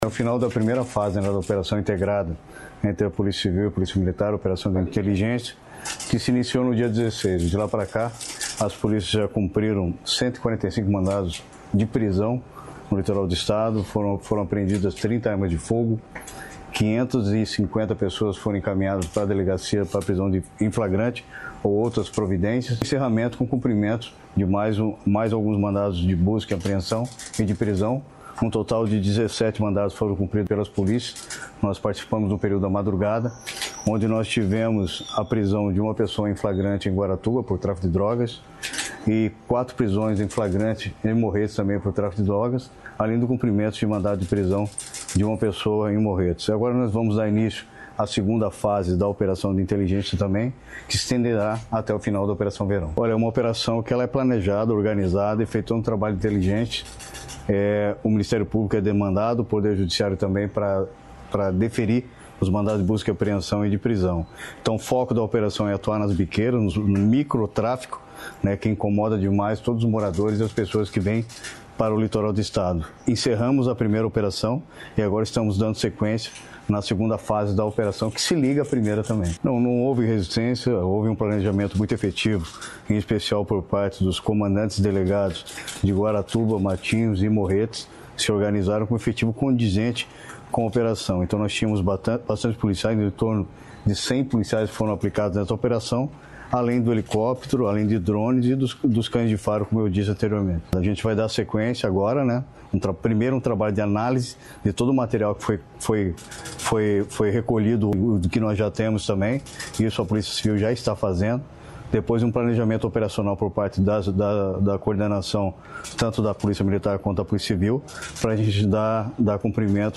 Sonora do secretário da Segurança Pública, Hudson Leôncio Teixeira, sobre primeira fase da Operação Integrada no Litoral